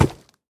Minecraft Version Minecraft Version snapshot Latest Release | Latest Snapshot snapshot / assets / minecraft / sounds / mob / piglin / step2.ogg Compare With Compare With Latest Release | Latest Snapshot
step2.ogg